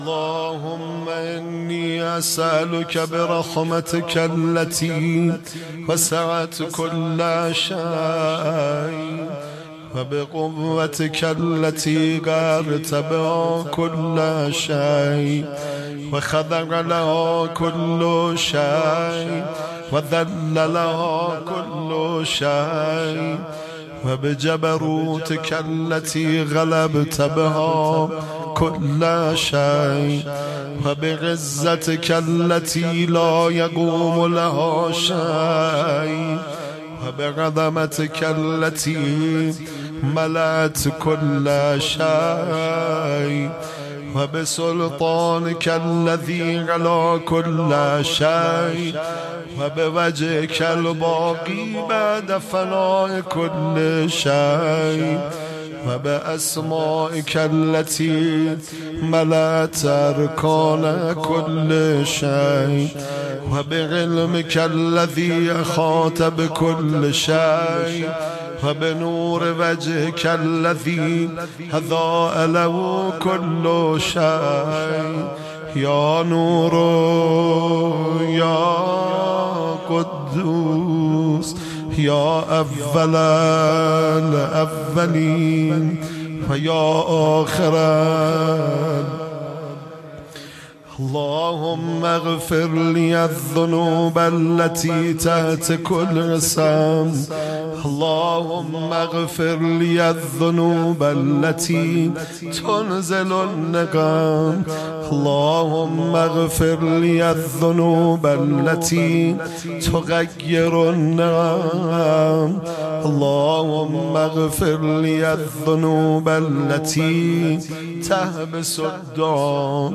زمزمه دعای کمیل